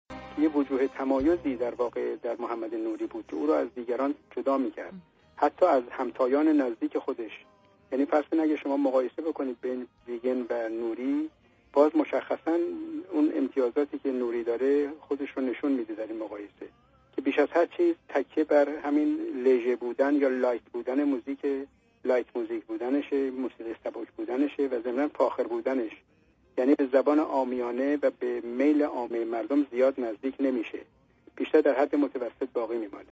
Radio Zamaneh Interview
The following words have been removed from the sentences in Part 6 and replaced with  a pluck: